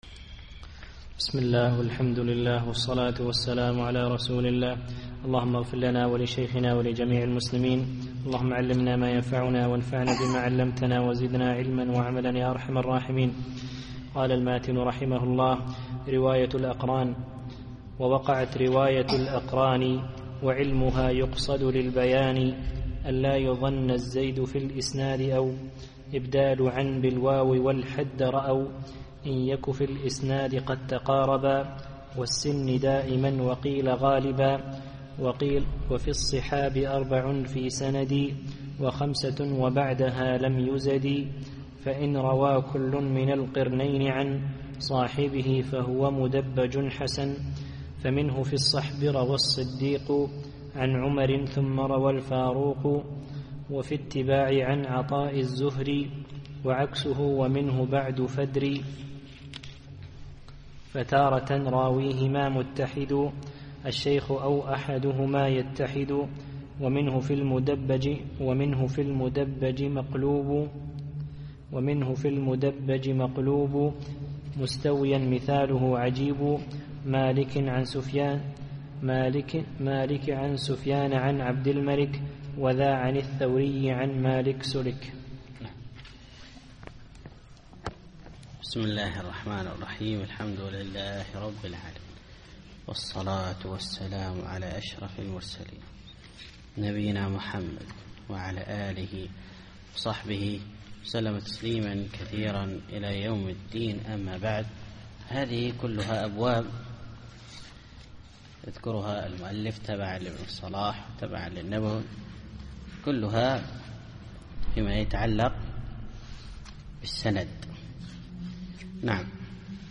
الدرس الثالث والثلاثون